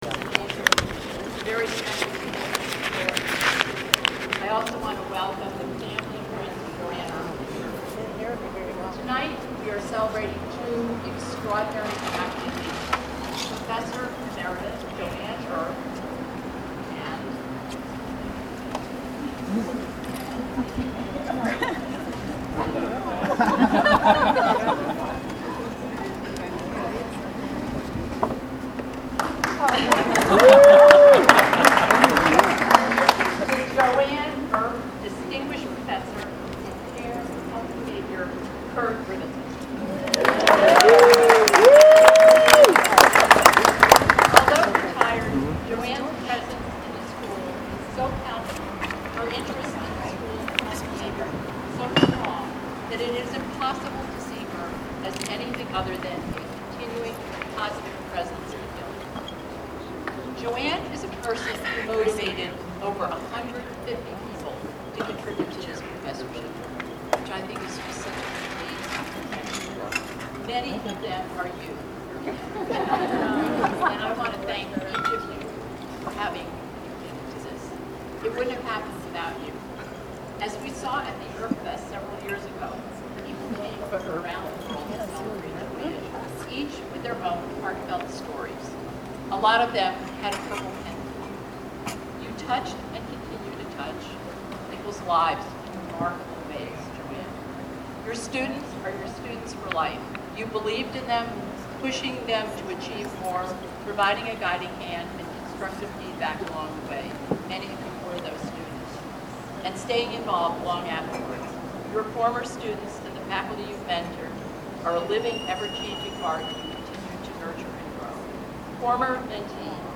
October 3, 2019, in the Bryan Courtyard of the Carolina Inn, Chapel Hill, NC.